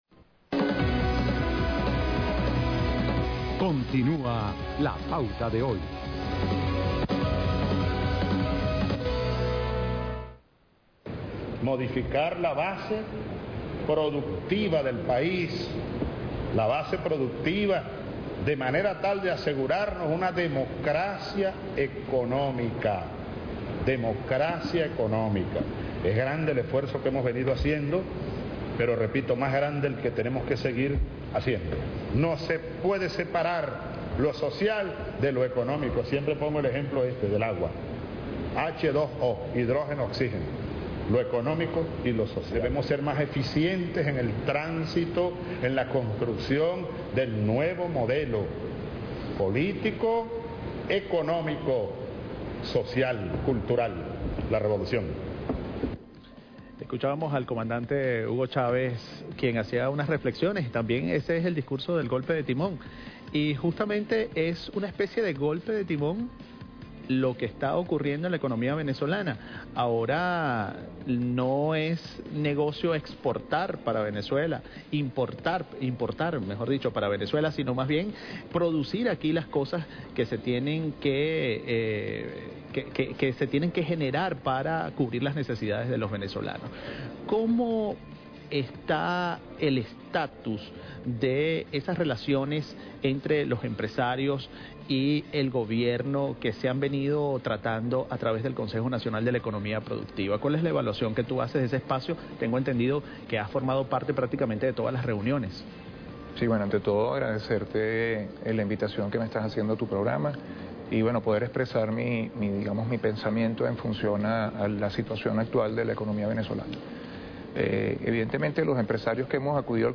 Durante el programa “La Pauta de Hoy”, detalló que el empresario debe cumplir su rol dentro de la sociedad y dejar a un lado el tema del beneficio personal, “el Consejo ha hecho un trabajo fundamental y algunos empresarios se mantienen reacios porque no entienden que tienen un espacio abierto hacia el diálogo con el gobierno nacional”.